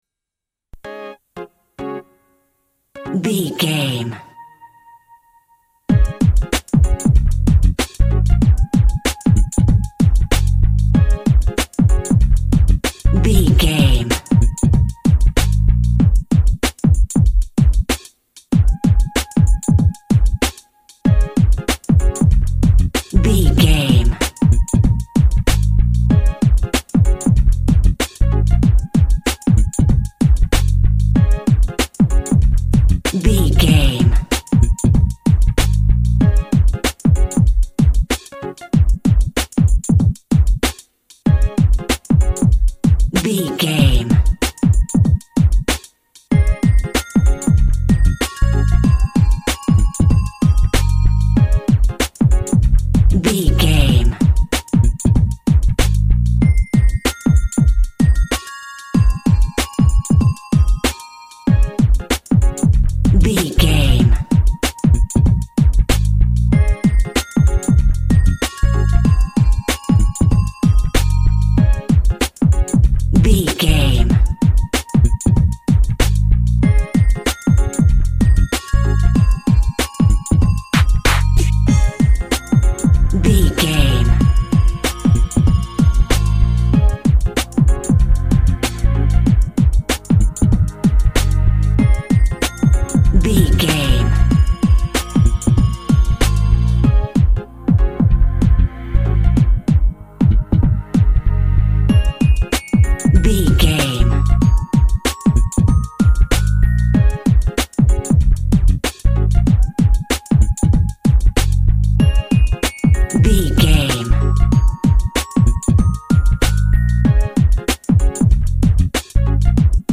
Hip Hop That's Heavy.
Ionian/Major
C#
synth lead
synth bass
hip hop synths
electronics